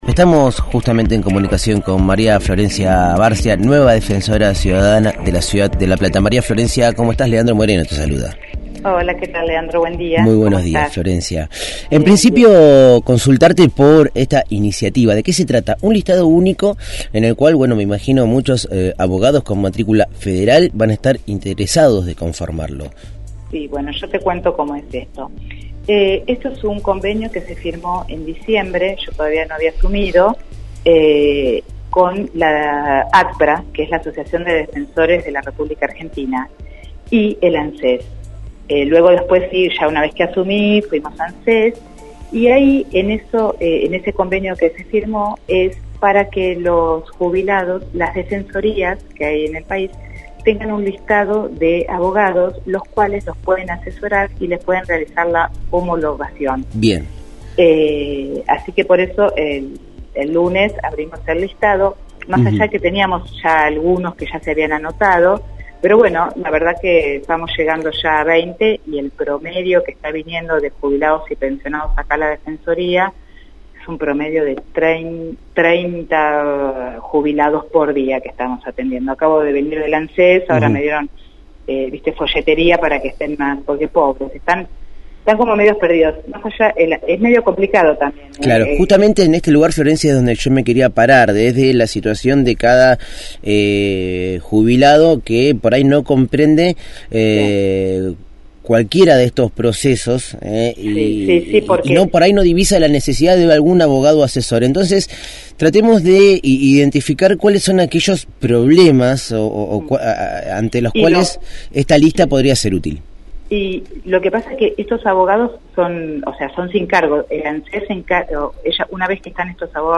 Entrevista a María Florencia Barcia, Defensora del Pueblo de La Plata, sobre la convocatoria a abogados para asesorar a jubilados. Programa: Verano Fatal.